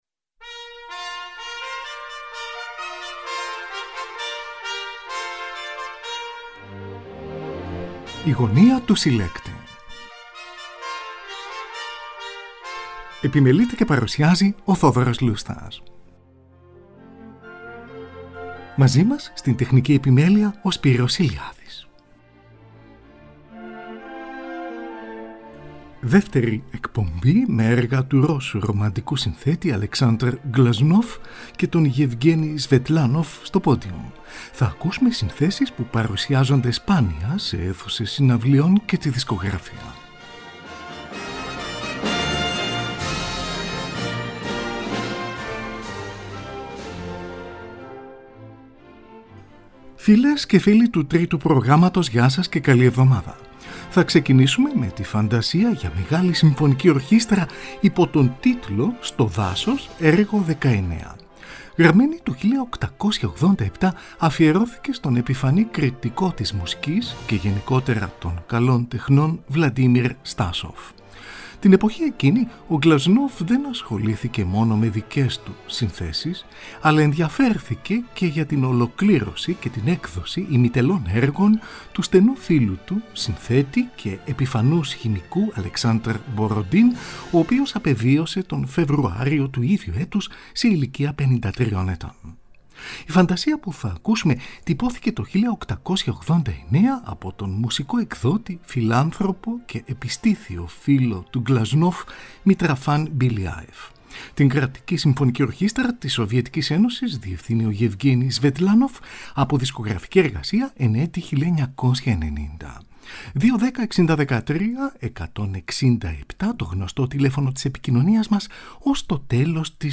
“Συμφωνικό σκίτσο” υπό τον τίτλο Σλαβική εορτή, έργο 26α.